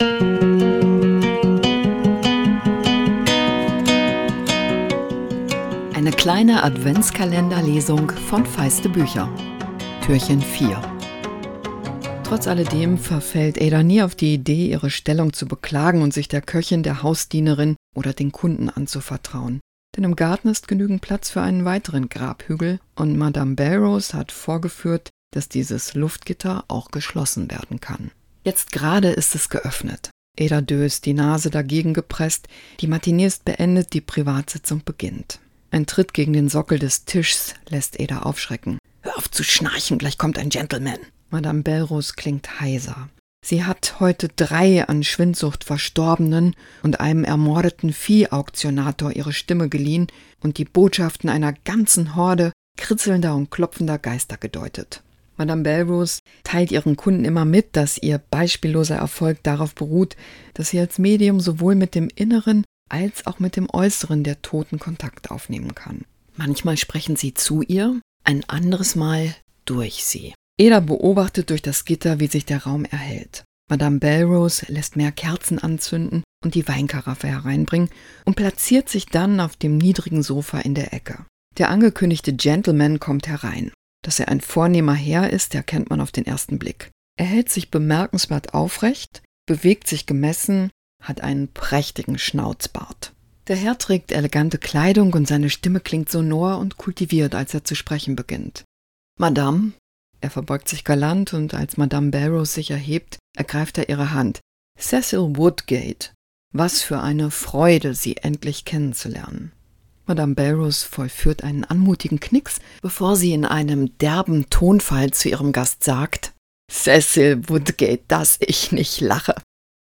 Adventskalender-Lesung 2024! Jess Kidd nimmt euch mit ins Leben des Waisenmädchens Ada Lark. Madam Bellerose, das Medium, bekommt feinen Besuch – oder verbirgt sich jemand ganz anderes dahinter?